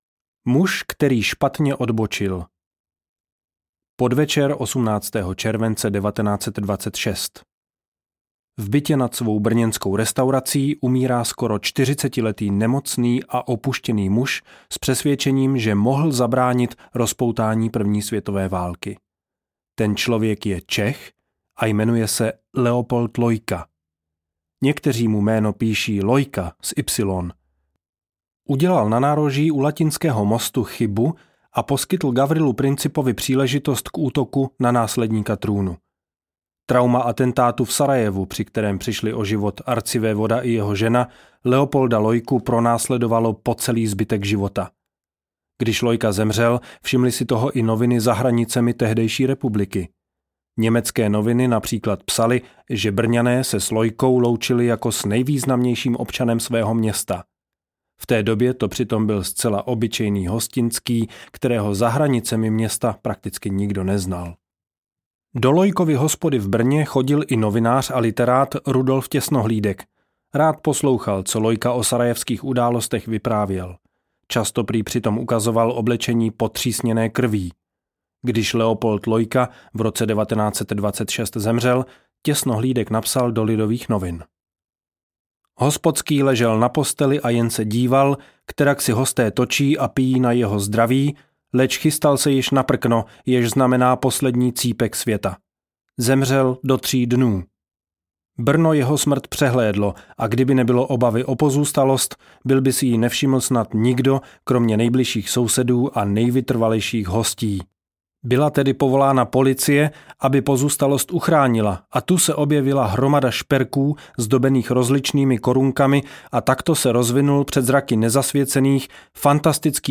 Tak nám zabili Ferdinanda audiokniha
Ukázka z knihy